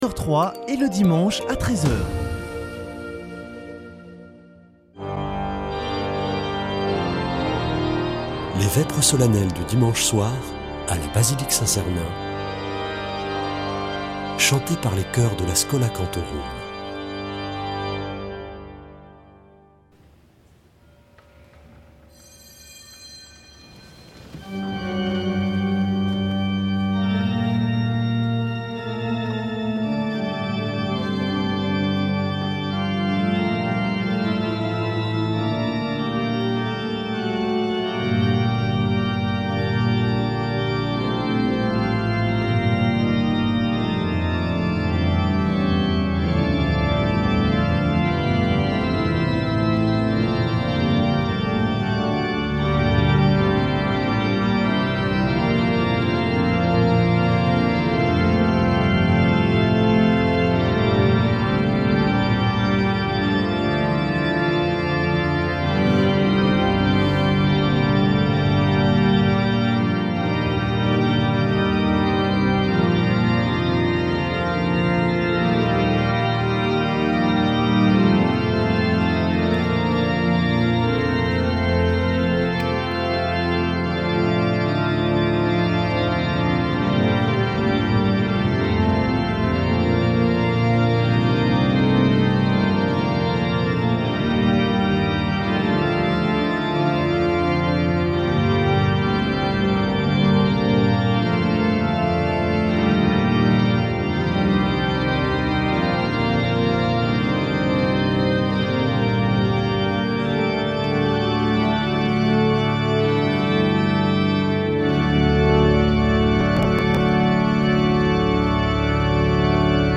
Vêpres de Saint Sernin du 19 nov.